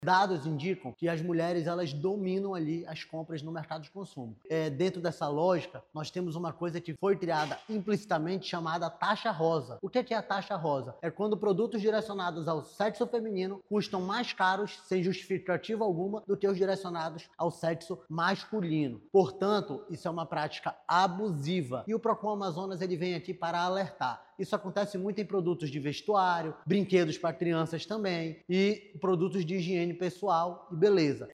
O diretor-presidente do Procon-AM, Jalil Fraxe, explica que a taxa rosa é uma prática abusiva que precisa ser combatida.